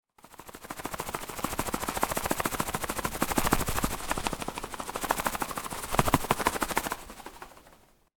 Звуки полета птиц
Шум крыльев летящей птицы